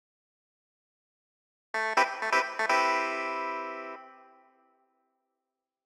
08 Clavinet PT 1-4.wav